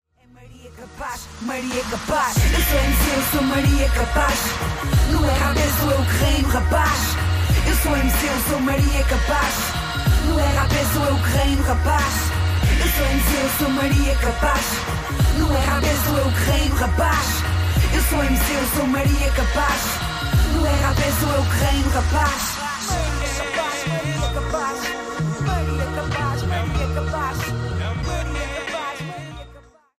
Hip-Hop / Urban